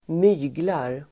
Uttal: [²m'y:glar]